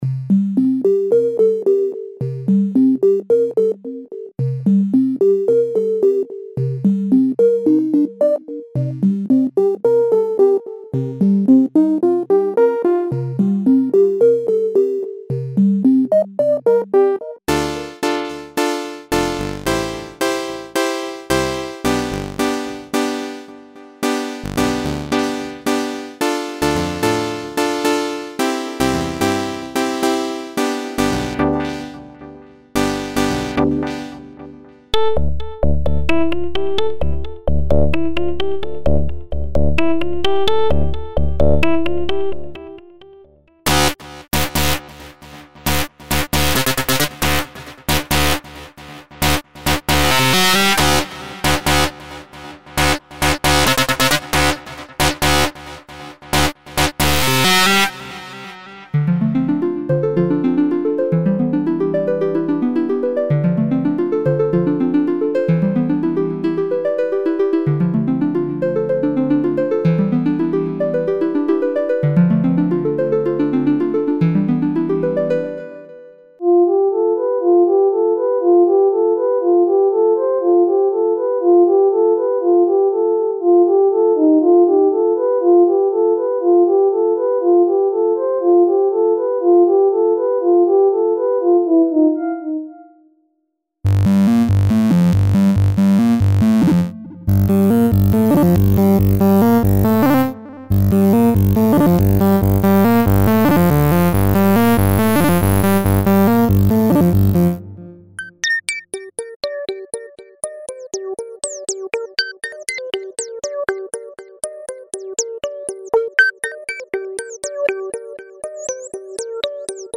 A PIII 1 Ghz PC with Logic Audio V5.5 was used .No mastering was done.
SYNTHS
No external FX used
blue_synth_bank_demo.mp3